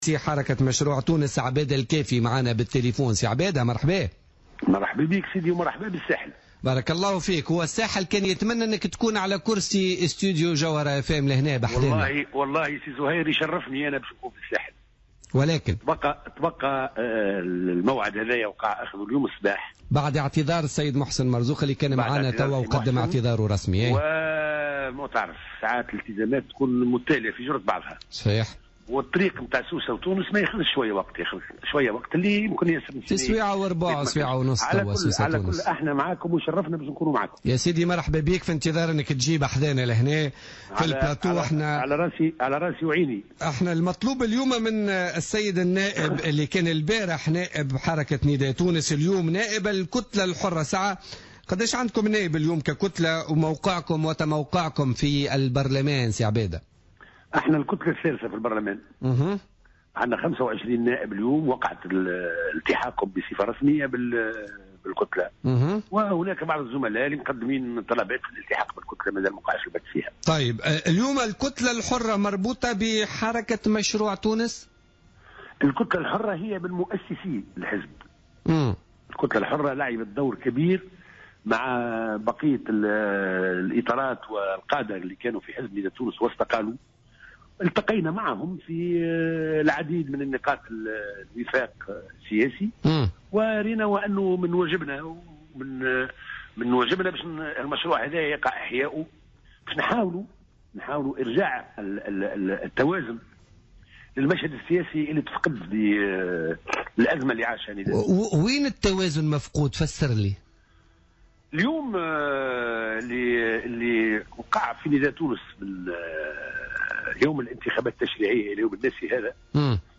Abada Kefi, président de la commission des droits et des relations extérieures à l'ARP et l'un des fondateurs du parti Harakat Machroû Tounès (Mouvement le Projet de la Tunisie) était l'invité ce jeudi 17 mars de l'émission Politica sur Jawhara Fm.